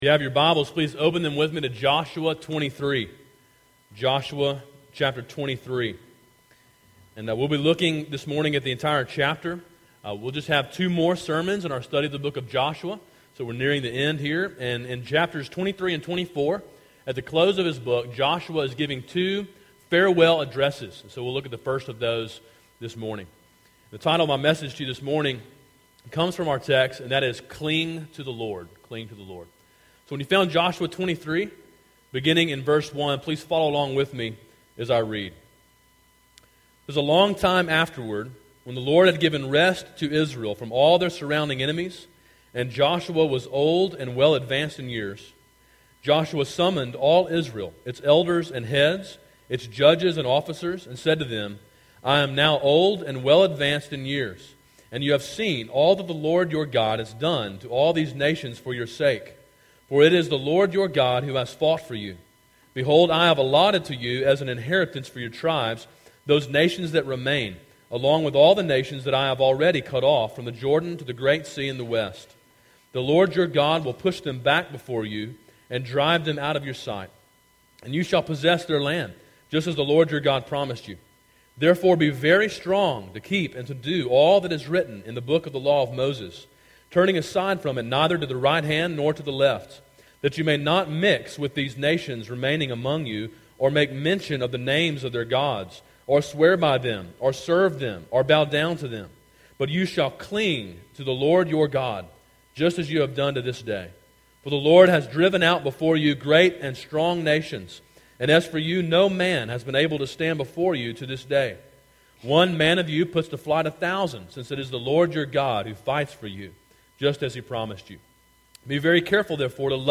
Sermon: “Cling to the Lord” (Joshua 23)